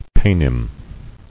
[pay·nim]